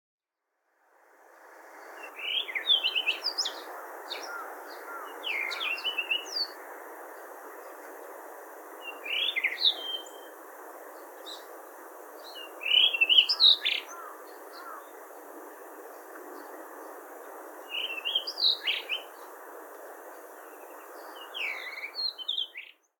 【録音②】　イソヒヨドリ（さえずり）　2025年3月
（鳴き声の特徴）ヒーチュリツチーチュルリルなど複雑な鳴声で囀る。